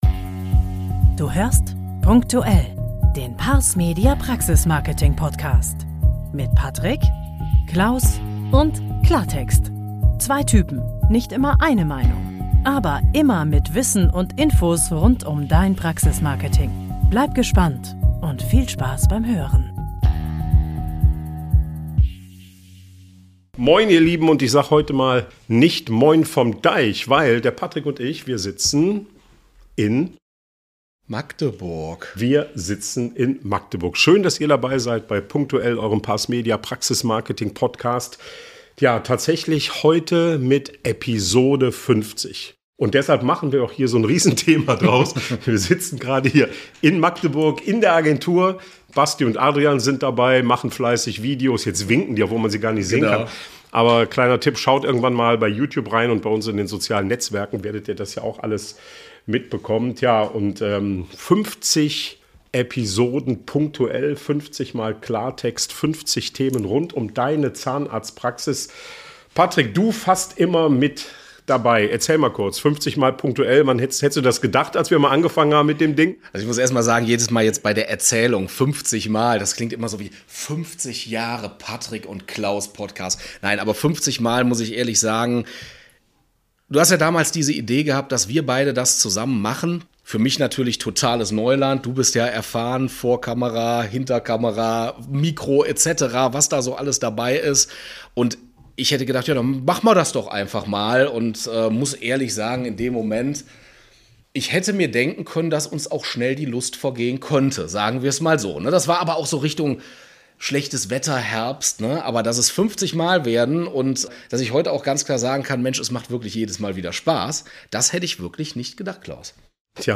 Und das haben wir dieses Mal alles live während der Aufnahme produziert – also nicht wundern, wenn euch einige Passagen komisch vorkommen.